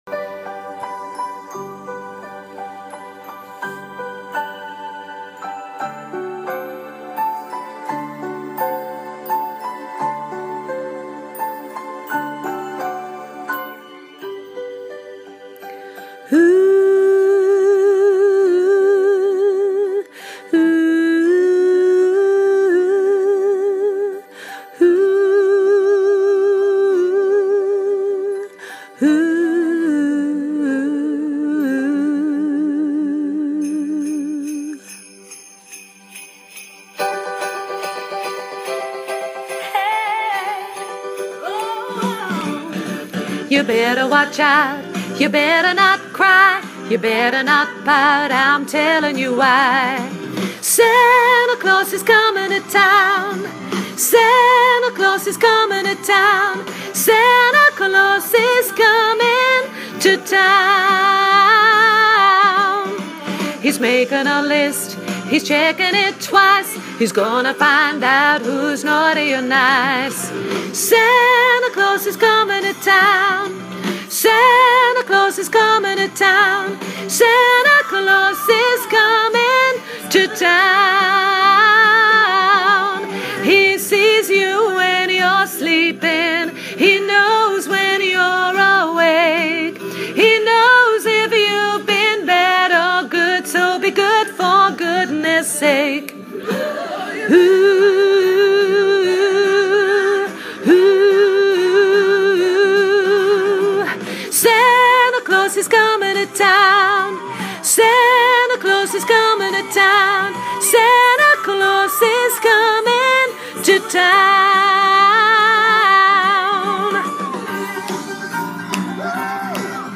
koortje alt